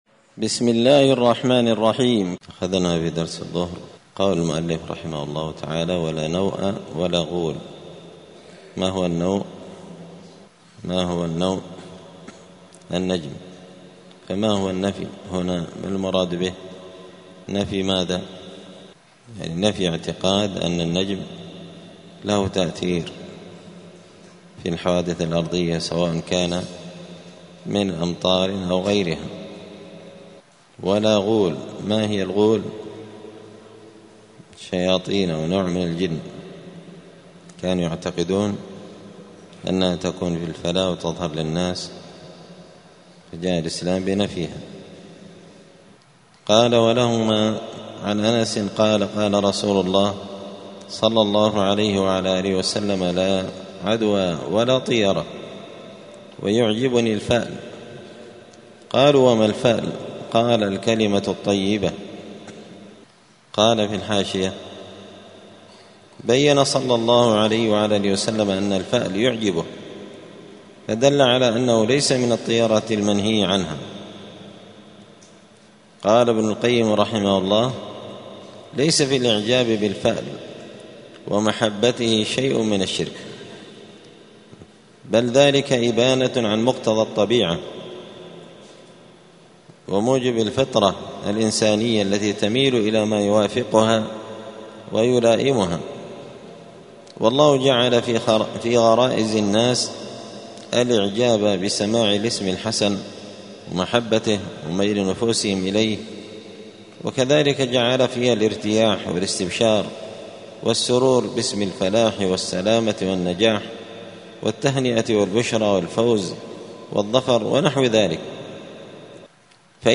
دار الحديث السلفية بمسجد الفرقان قشن المهرة اليمن
*الدرس الثامن والسبعون (78) {تابع لباب ما جاء في الطيرة}*